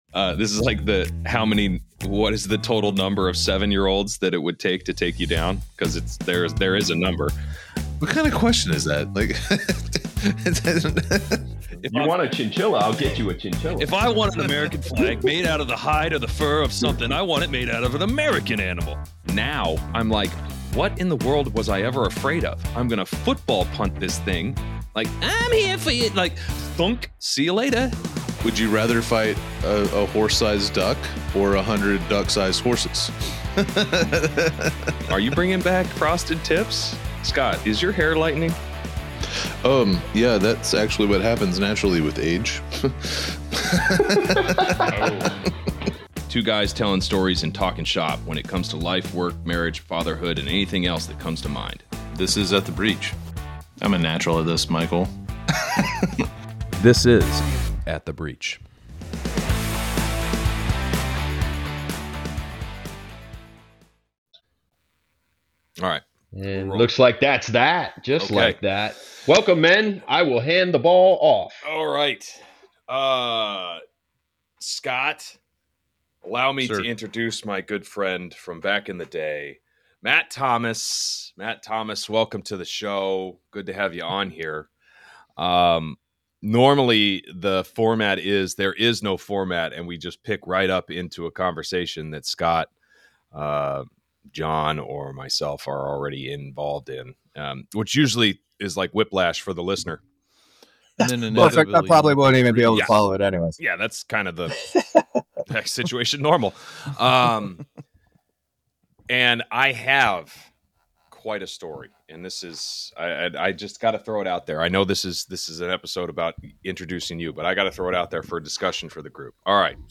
Play Rate Listened List Bookmark Get this podcast via API From The Podcast Welcome to At the Breach, a podcast hosted by two veterans who juggle the roles of fathers, husbands, and Americans. Join them each episode as they explore diverse topics, sharing personal stories, insights, and some laughs.